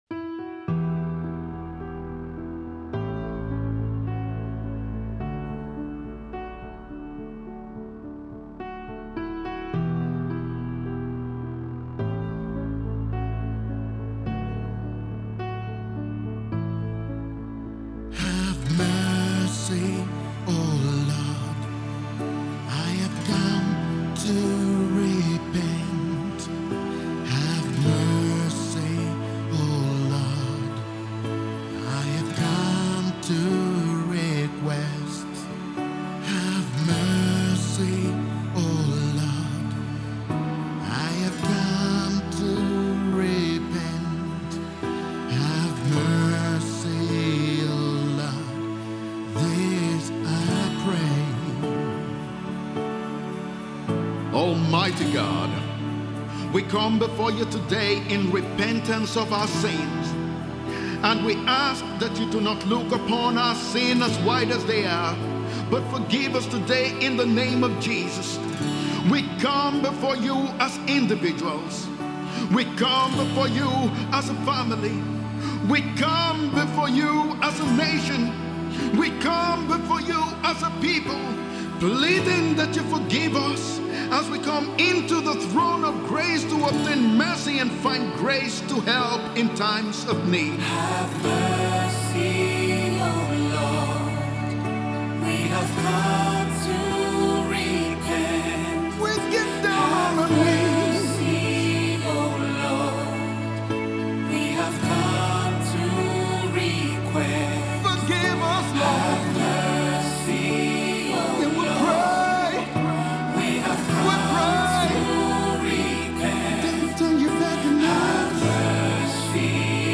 Gospel artist